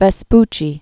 (vĕs-pchē, -py-), AmerigoLatin nameAmericus Vespucius.1454-1512.